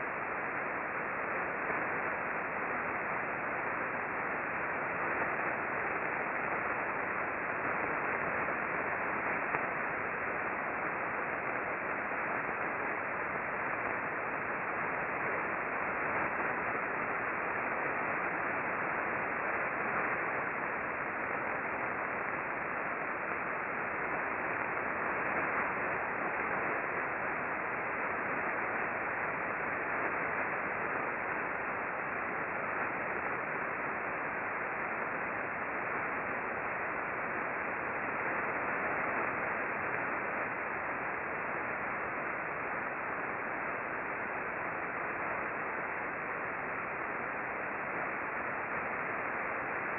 The ambient RF noise level in this direction is fairly high (over 300k K).
We observed mostly S-bursts that shifted from receiver to receiver during the bursting periods.